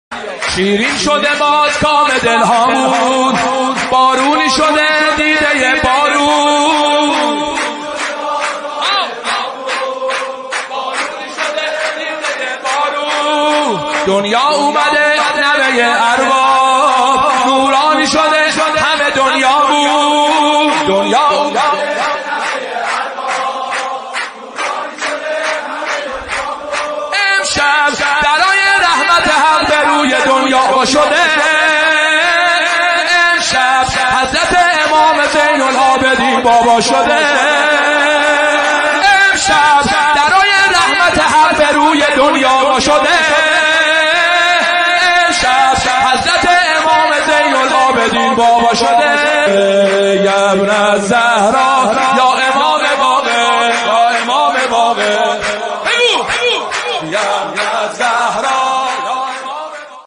نماهنگ | مولودی حلول ماه رجب و میلاد امام باقرعلیه السلام | ضیاءالصالحین